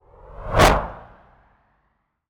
bullet_flyby_designed_04.wav